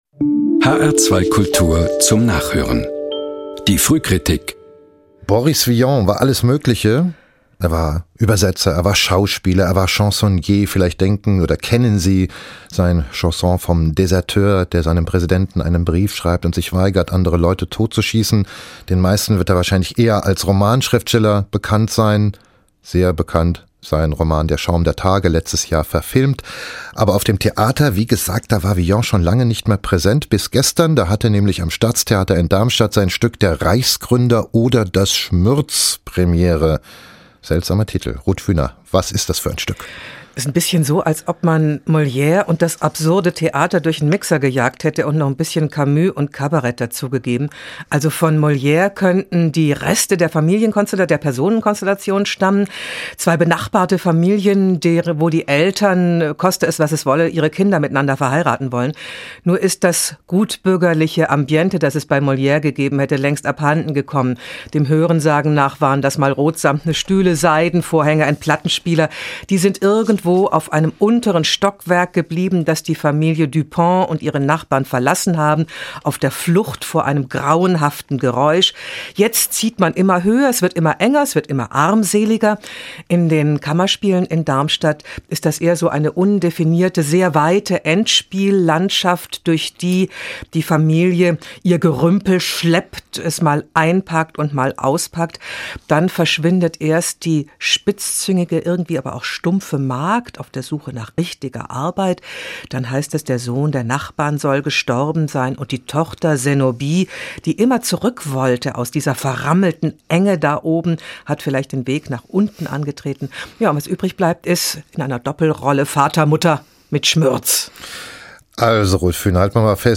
radio critic, HR2, 07.11.2014